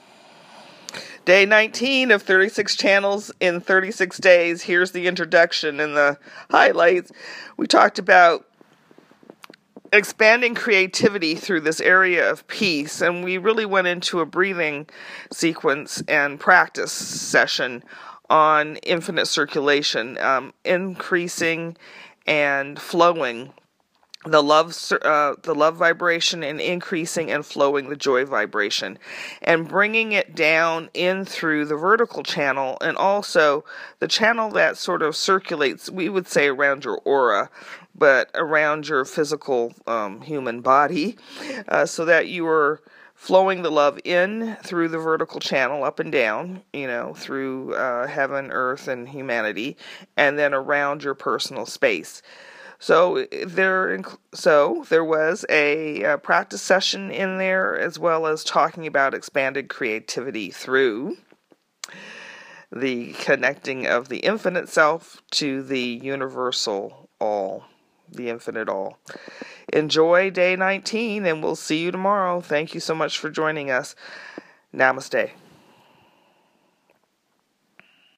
DAY 19: The Infinite Cycle breath and transformation sequence is included in this session.
Each Channel is RECORDED in the morning and then POSTED on the 36 CHANNELS IN 36 DAYS web page later in the day.